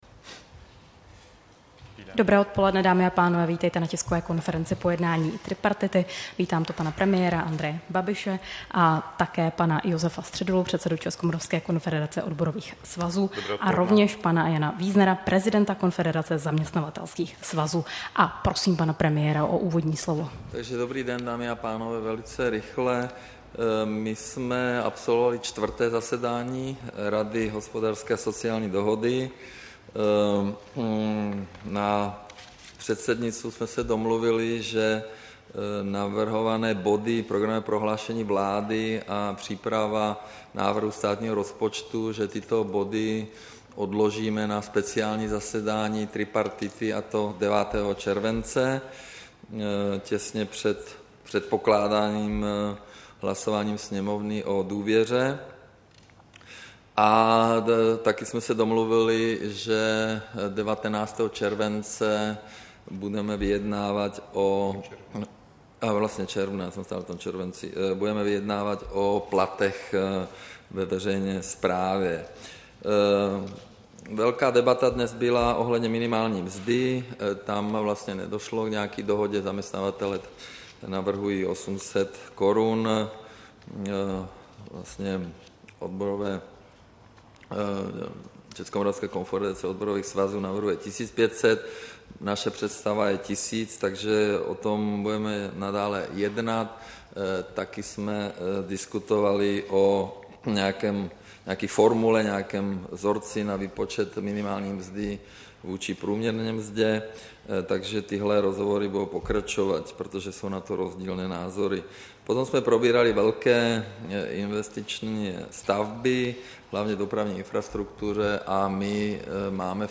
Tisková konference po jednání tripartity, 13. června 2018